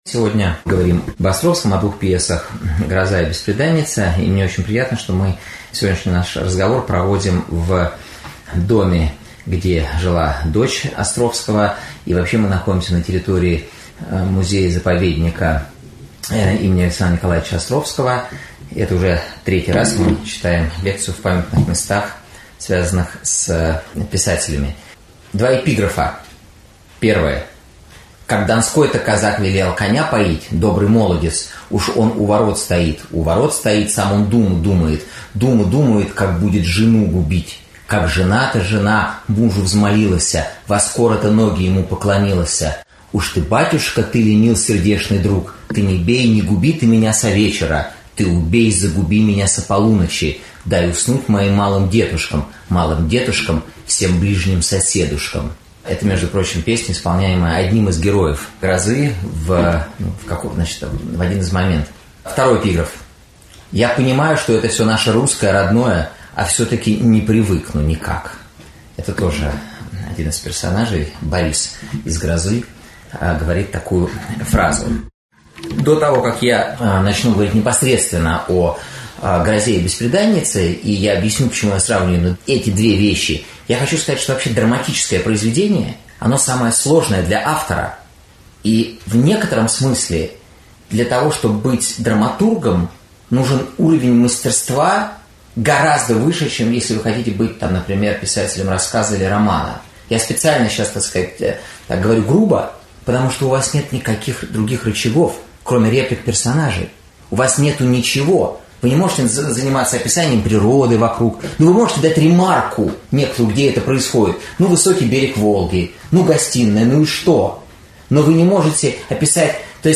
Аудиокнига Островский. Гроза. Бесприданница. «И мрачной бездны на краю», или можно ли спастись с помощью громоотвода.